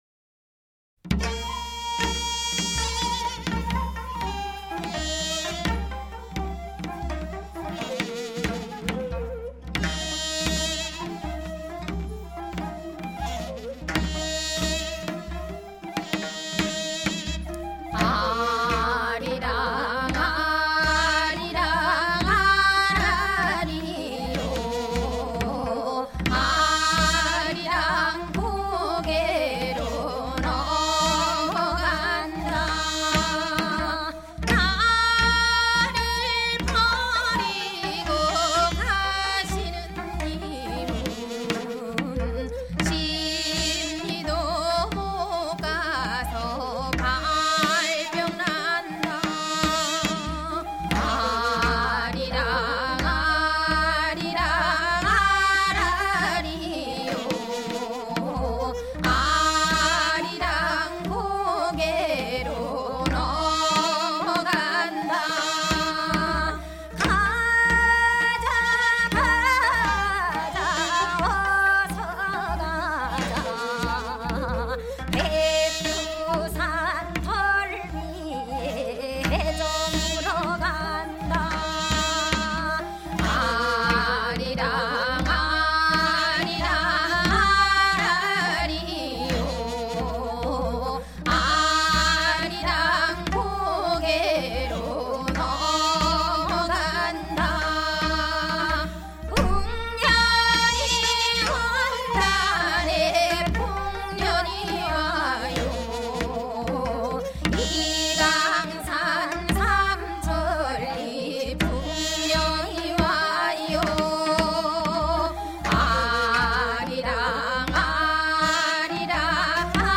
[10/9/2009]原生态系列 朝鲜.韩国原生态民歌 （首尔原生态歌手演唱） 绝对够土！